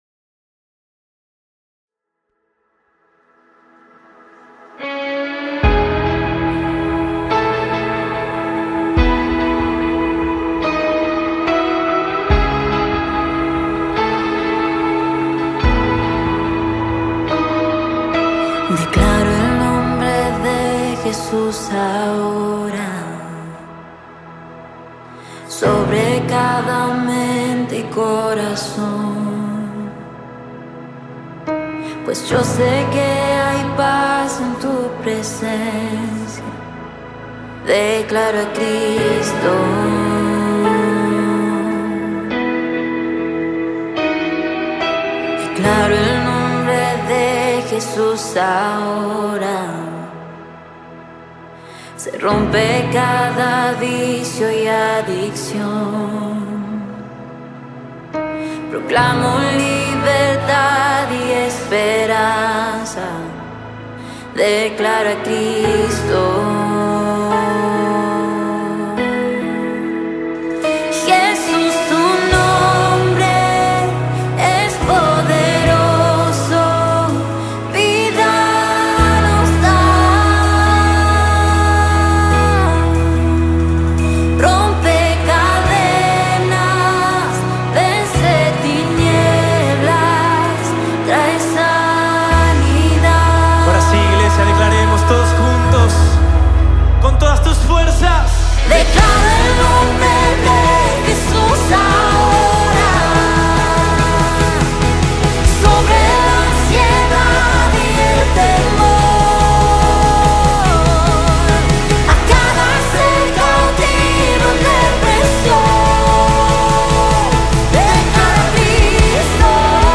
124 просмотра 36 прослушиваний 2 скачивания BPM: 74